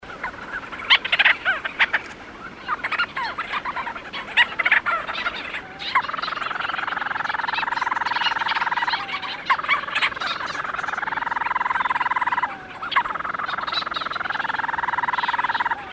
Leach's Storm-Petrel (Oceanodroma leucorhoa)
Play MP3  Flight calls and "purring" from burrows. Gull Island, 2002.